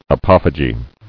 [a·poph·y·ge]